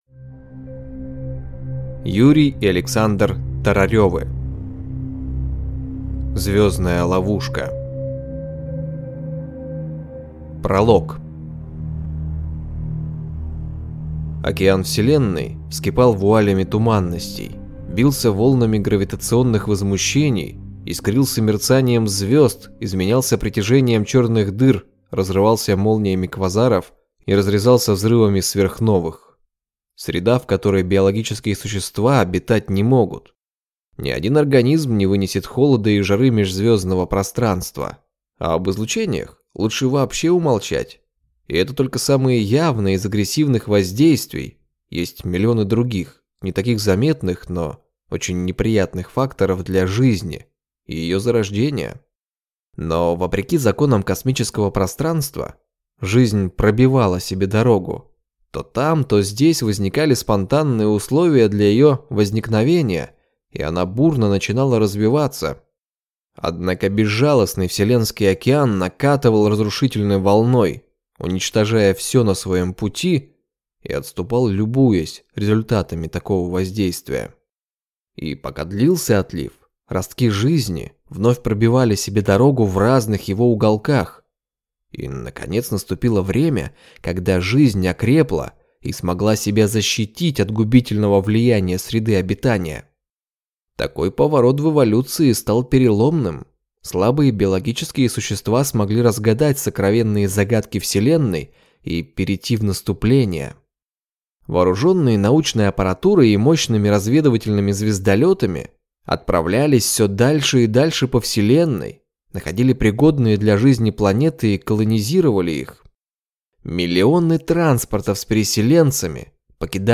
Аудиокнига Звездная ловушка | Библиотека аудиокниг